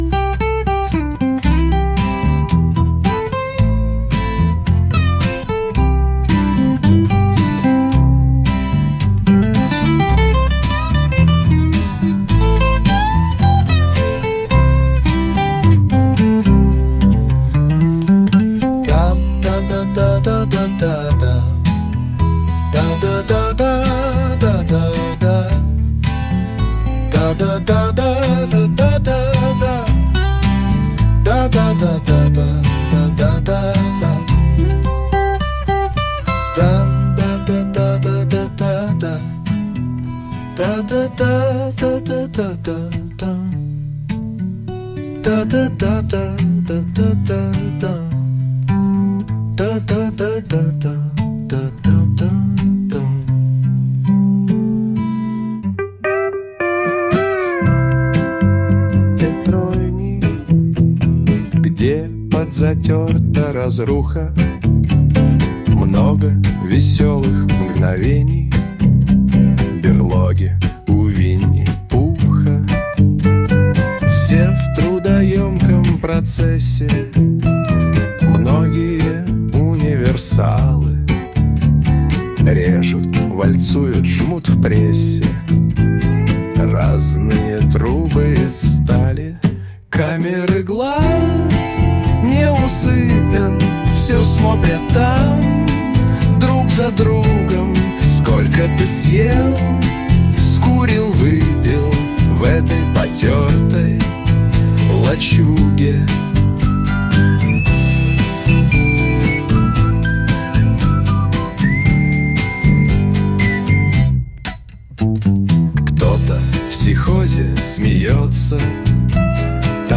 • 4: Рок